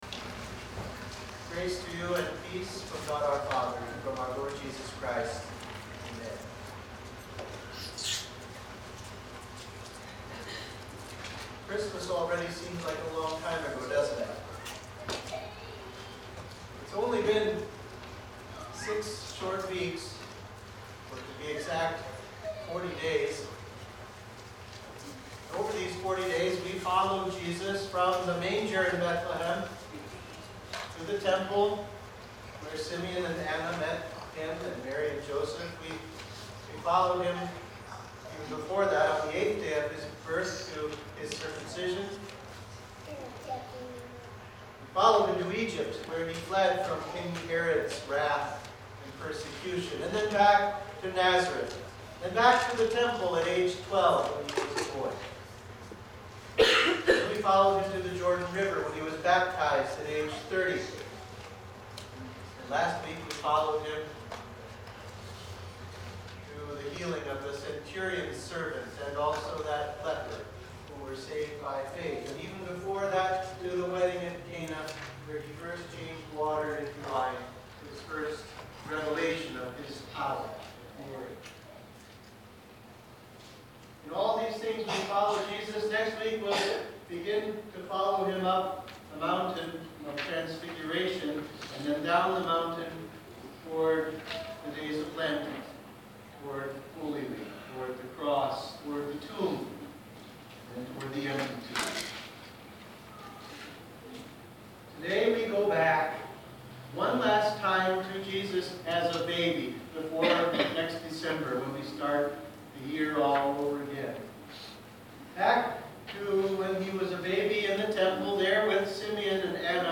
Sermon for the Presentation of Our Lord and Purification of Mary